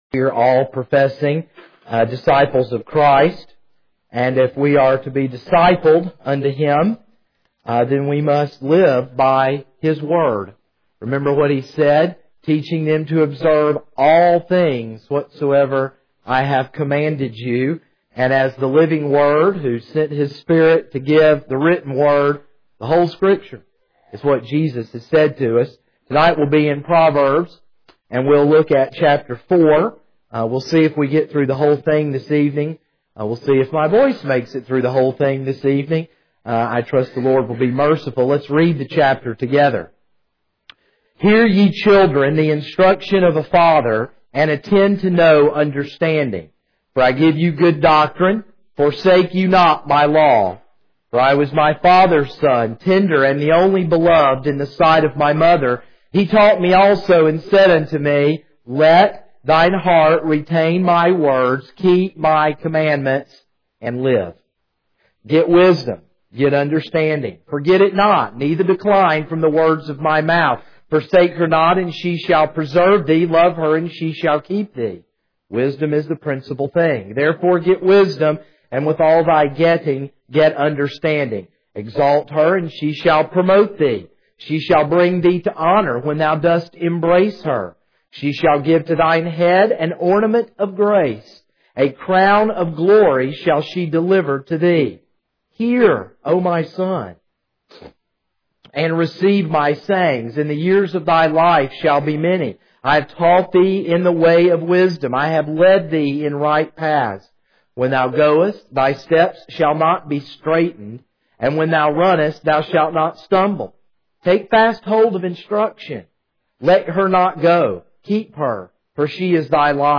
This is a sermon on Proverbs 4.